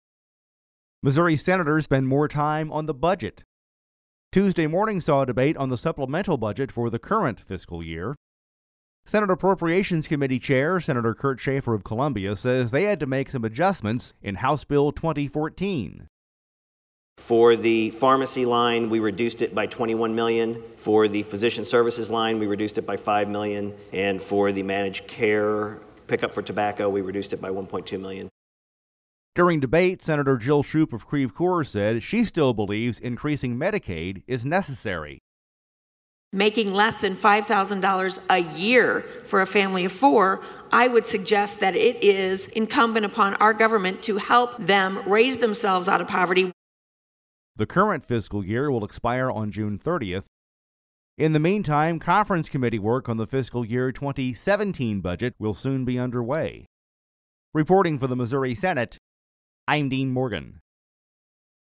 We’ve included actualities from Senate Appropriations Committee Chair — Sen. Kurt Schaefer, R-Columbia — and Sen. Jill Schupp, D-Creve Coeur,
feature report